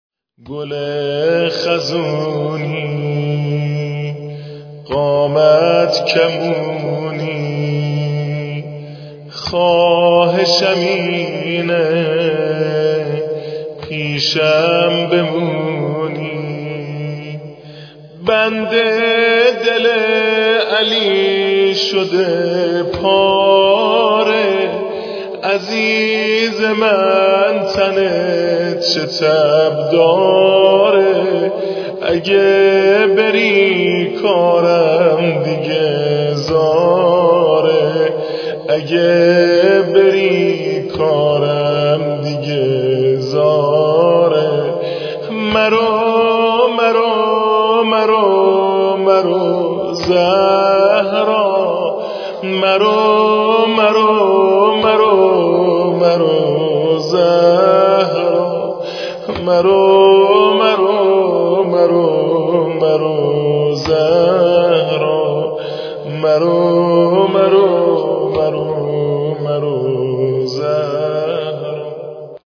فاطمه الزهرا بستر واحد گل خزونی